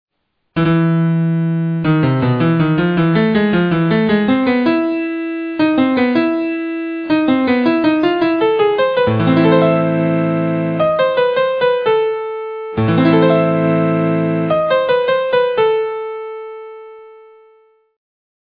The initial repeat of E, on which the next two bars still insist, indicates the tonal importance of the note (dominant, in the example), whereas the arpeggios at bars 4 and 5 state the key of A minor.